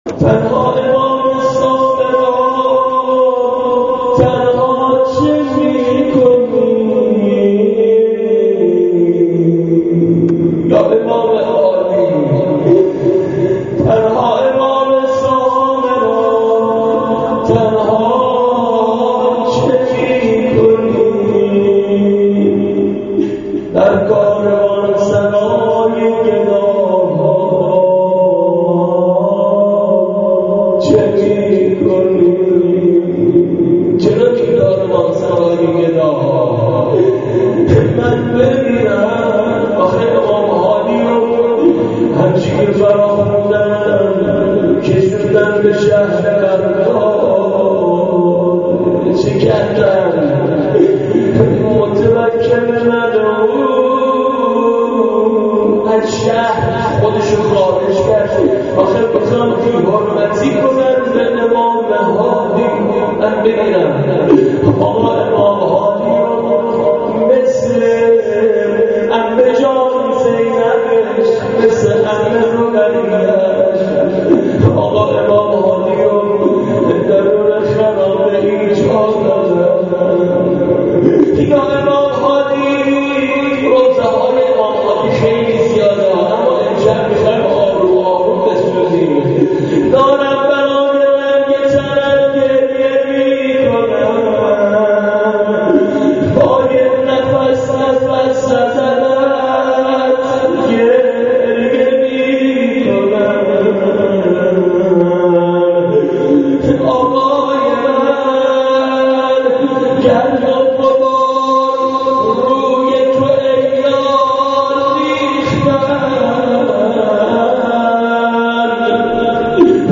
روضه امام هادی مرد خدا کجا و این همه تحقیر وای من.MP3